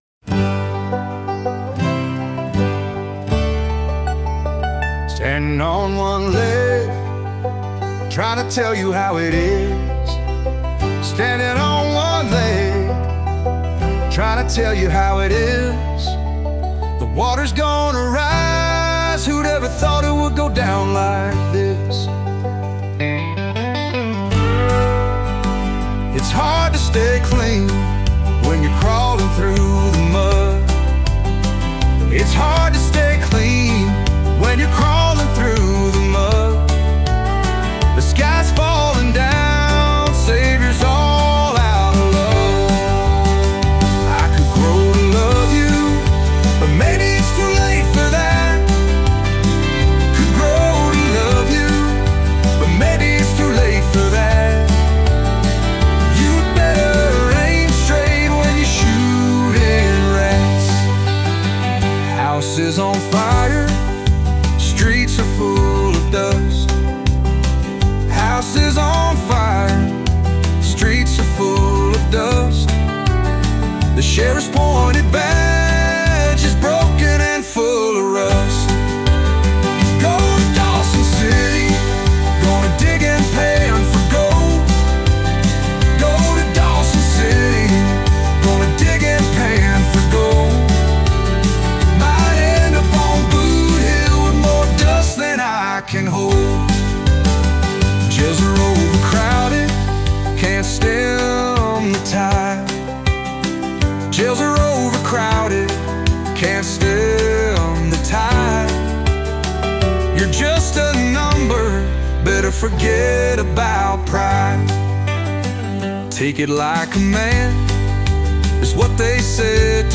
a gritty, melancholic country song